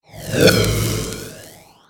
GS13NG/sound/magic/Charge.ogg at 558a989d8a957504ea3fe0e1a5b3f21ba34e8597
Charge.ogg